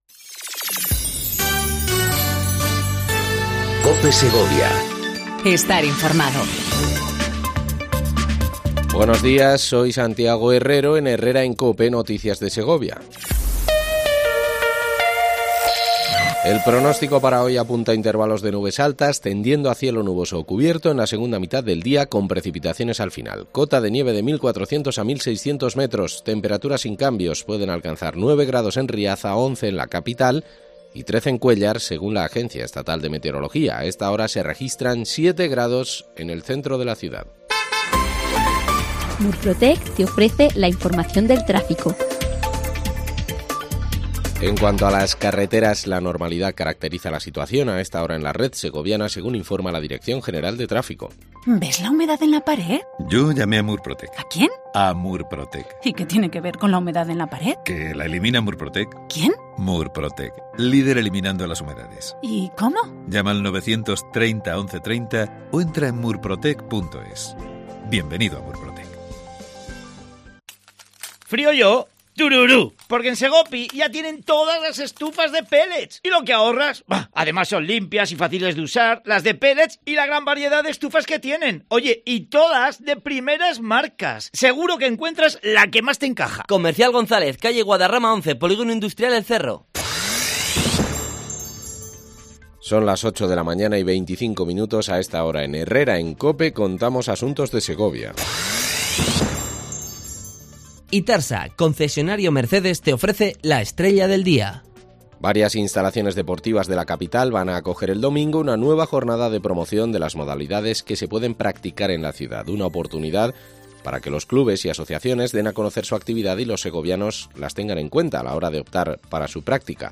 AUDIO: Segundo informativo local en cope segovia